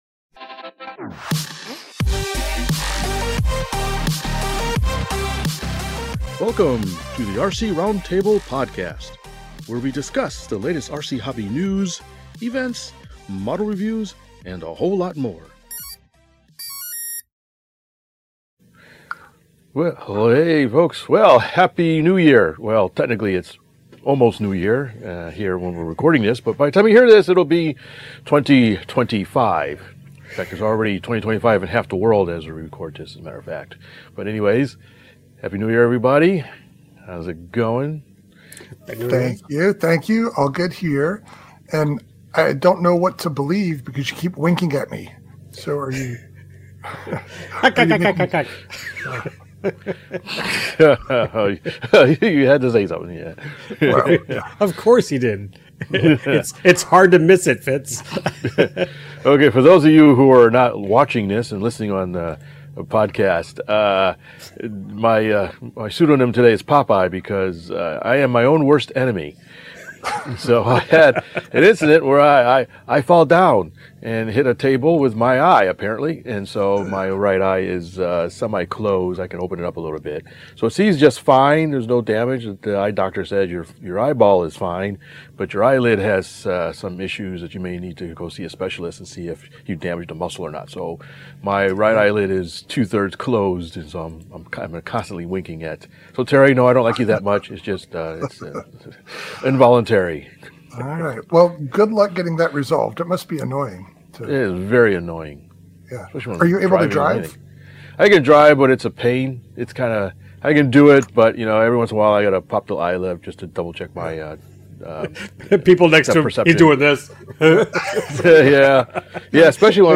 We use the recording sessions to catch up with each other, get things off our chests, and throw around a few light-hearted insults. We start each episode with a short list of topics we plan to cover, but you never know where the conversation will go.